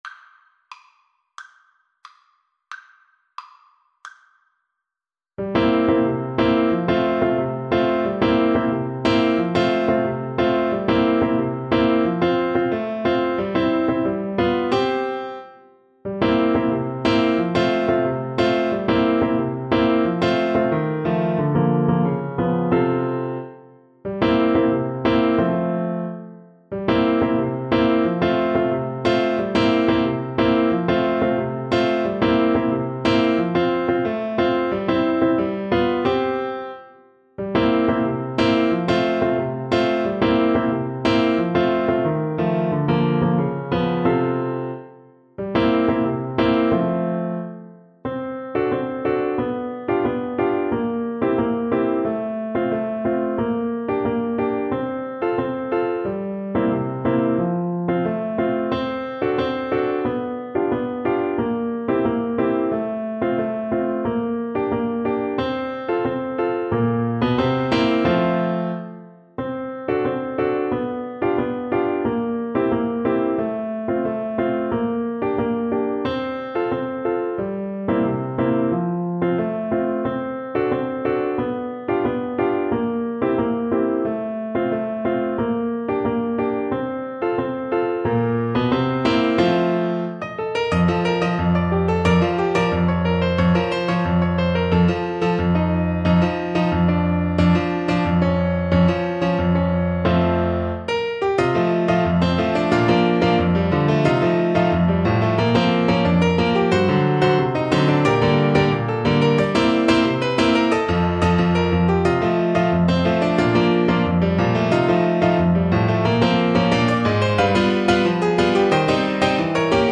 Pieces in 2-4 Time Signature
Clarinet pieces in F major
Brazilian